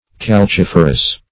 Calciferous \Cal*cif"er*ous\, a. [L. calx, calcis, lime +